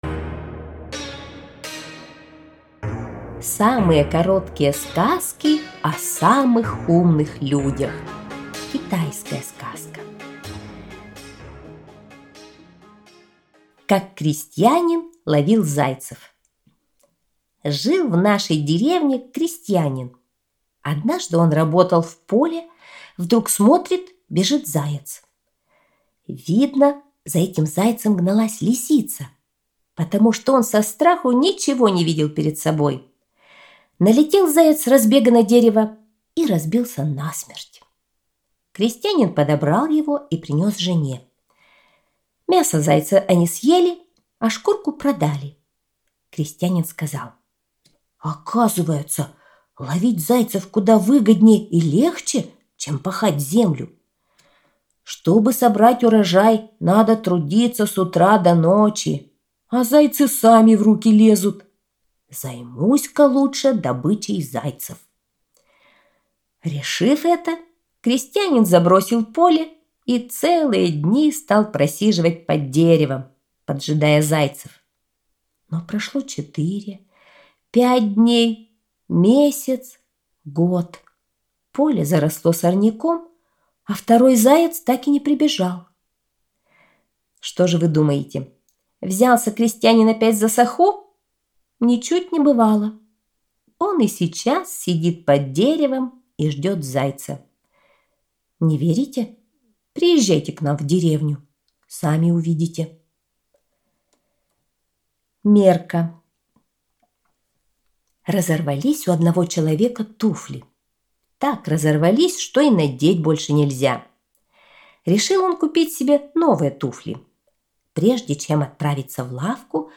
Самые короткие сказки о самых умных людях – китайская аудиосказка
Аудиокнига в разделах